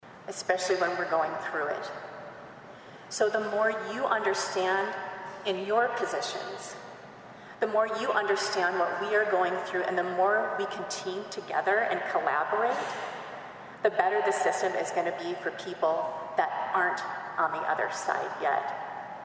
THIS IS NATIONAL CRIME VICTIMS’ RIGHTS WEEK AND WOODBURY COUNTY OFFICIALS HELD A GATHERING AT THE COURTHOUSE THURSDAY TO CALL ATTENTION TO LOCAL VICTIMS AND WHAT THEY HAVE BEEN THROUGH.